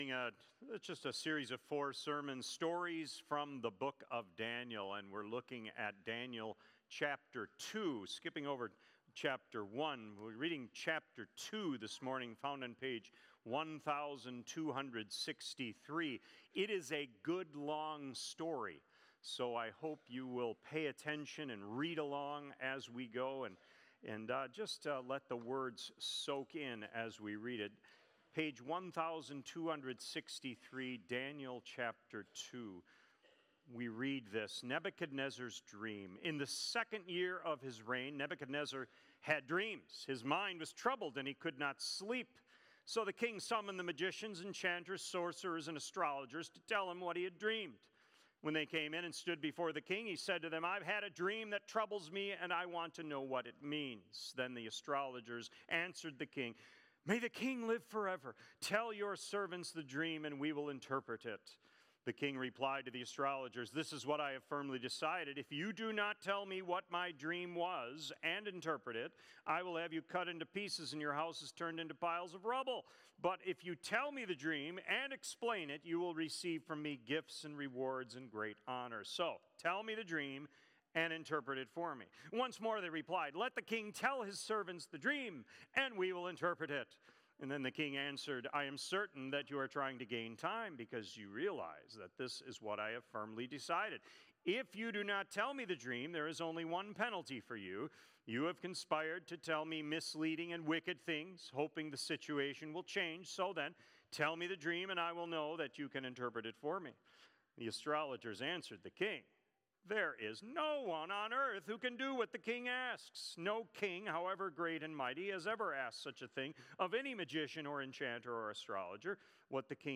Sermons | Faith Community Christian Reformed Church